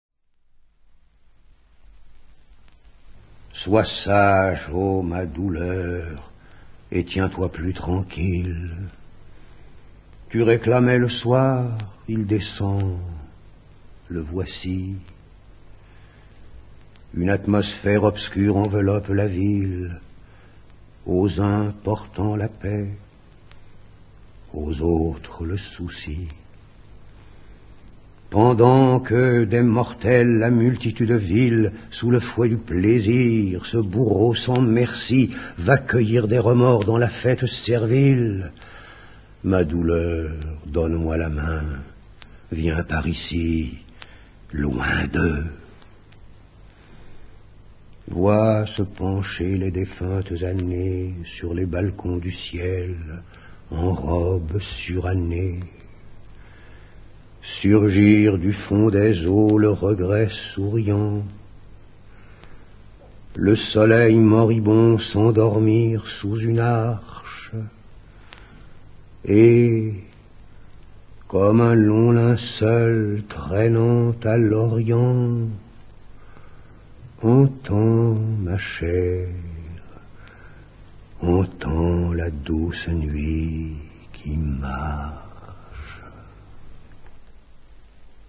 dit par Pierre BLANCHAR